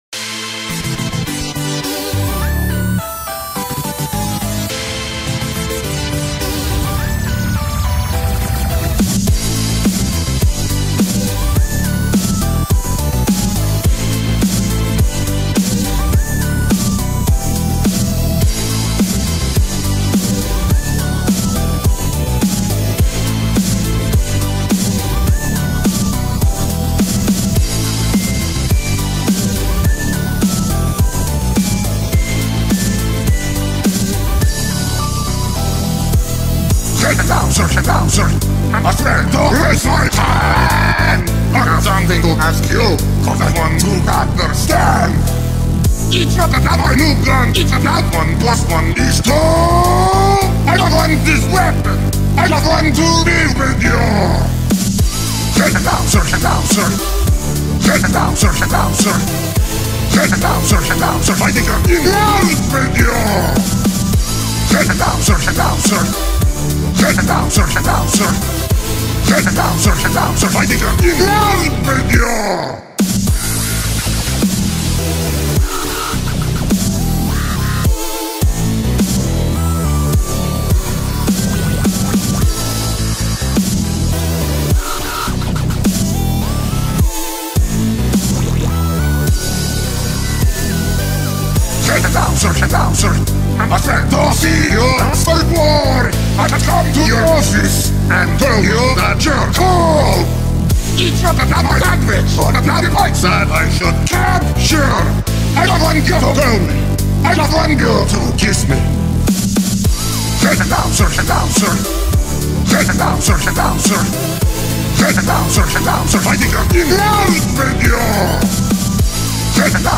So yeah, here it is, all better-sounding and everything!